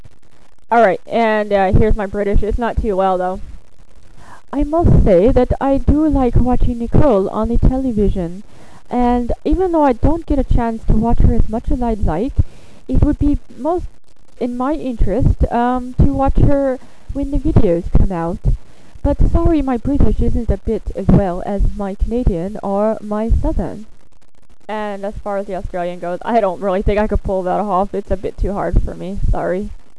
Here are a list of Accents and Impersonations I can do...
British
britishaustralian.wav